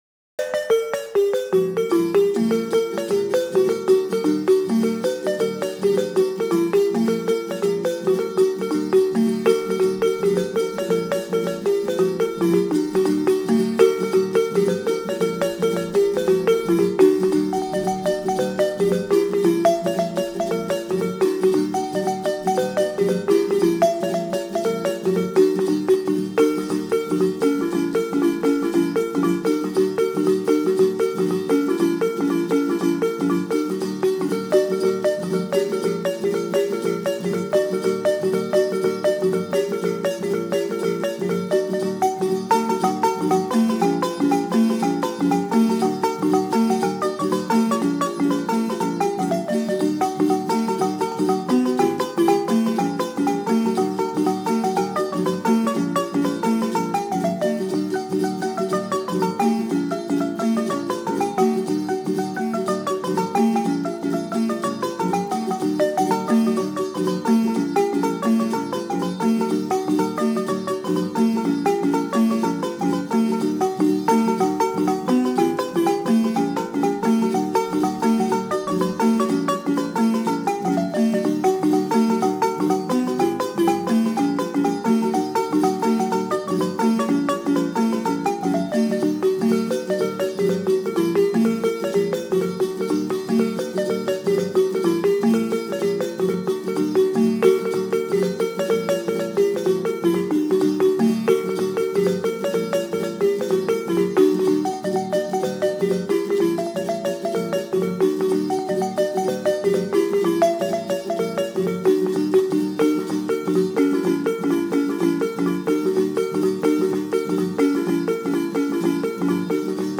NICARAGUAN MUSIC
is more upbeat.